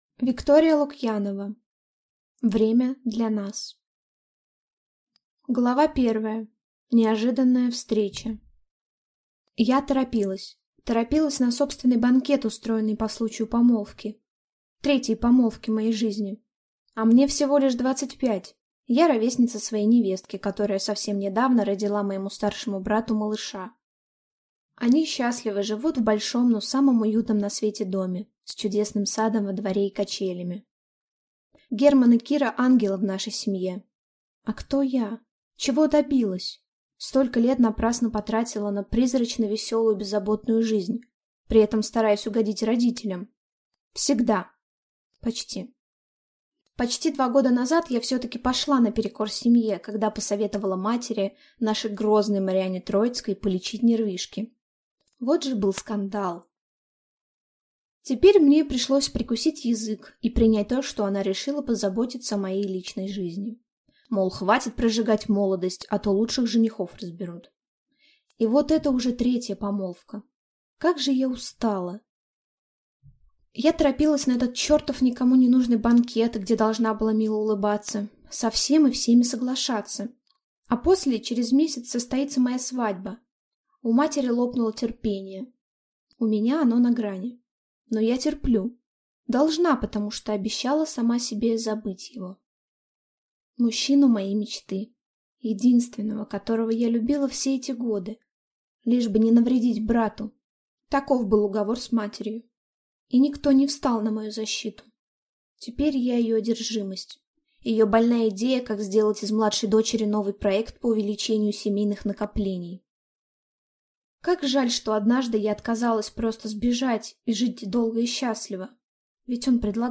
Аудиокнига Время для нас | Библиотека аудиокниг